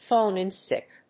アクセントphóne ìn síck